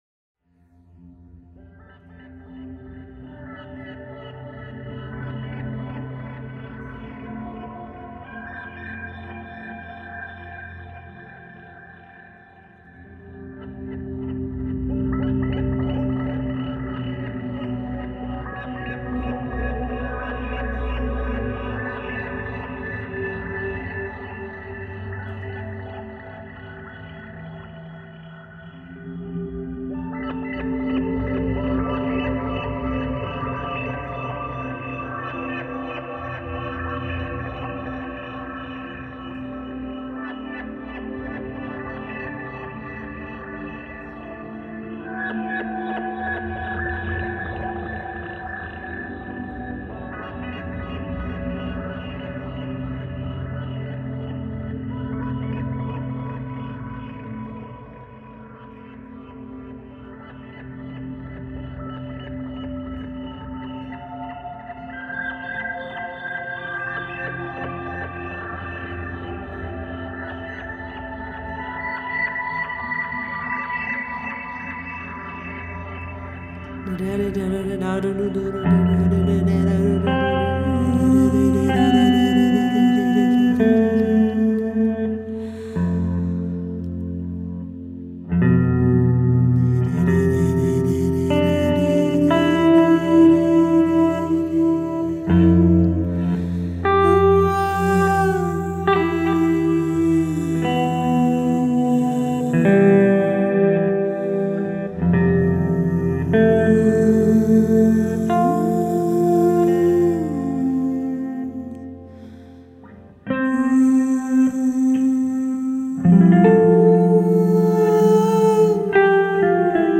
é um álbum de guitarra e voz